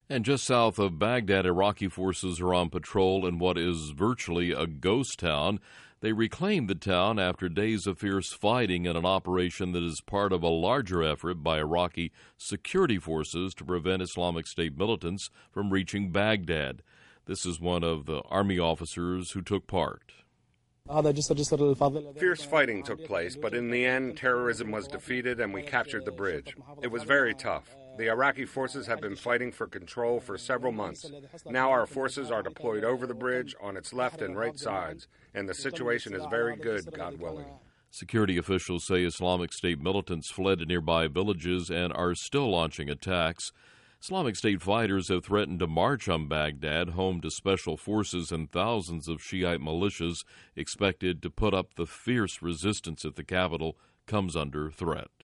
Just South Of Baghdad ...Iraqi Forces Are On Patrol In A Virtual Ghost Town. They Re-Claimed The Town After Days Of Fierce Fighting, In An Operation That Is Part Of A Larger Effort By Iraqi Security Forces To Prevent Islamic State Militants From Reaching Baghdad. This Is One Of The Army Officers Who Took Part.